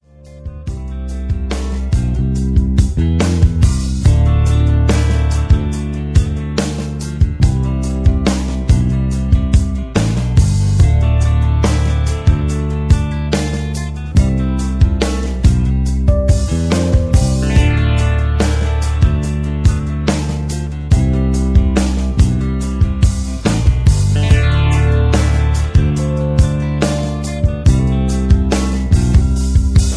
Key-Dm) Karaoke MP3 Backing Tracks
Just Plain & Simply "GREAT MUSIC" (No Lyrics).